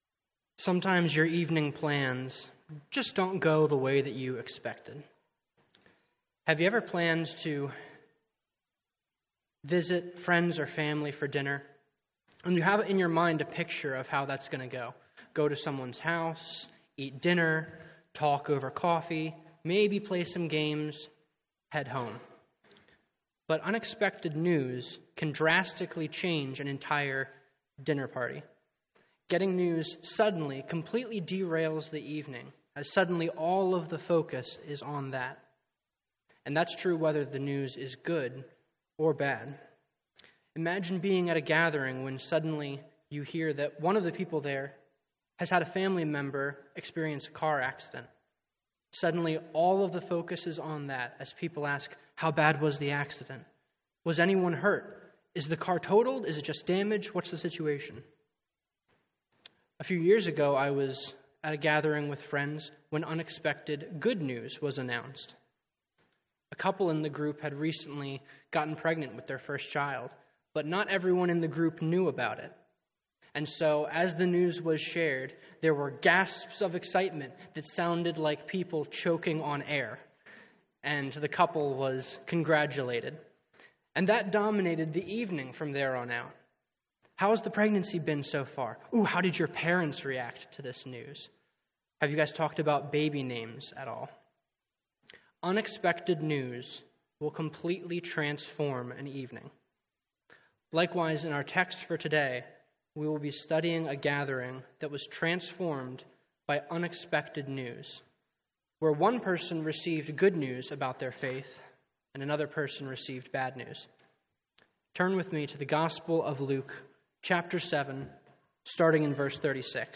Sermons on Luke 7:36-50 — Audio Sermons — Brick Lane Community Church